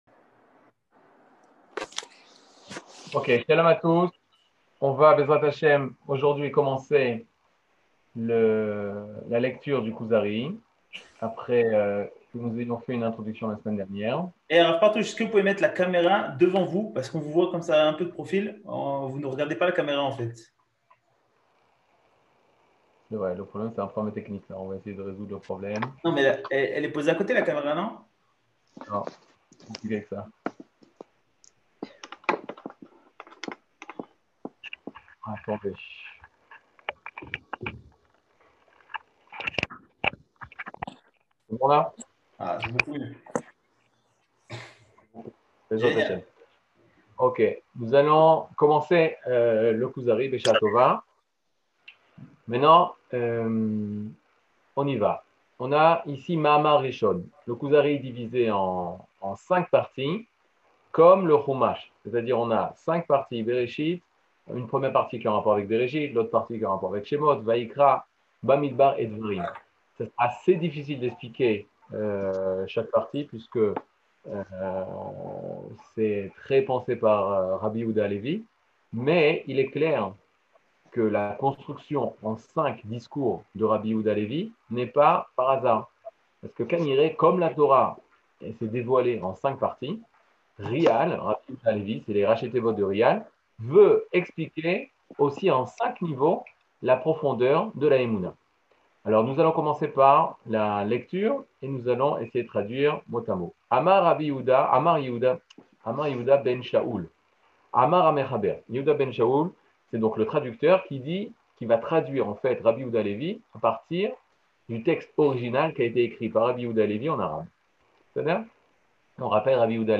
Catégorie Le livre du Kuzari partie 2 01:00:37 Le livre du Kuzari partie 2 cours du 16 mai 2022 01H 00MIN Télécharger AUDIO MP3 (55.5 Mo) Télécharger VIDEO MP4 (166.67 Mo) TAGS : Mini-cours Voir aussi ?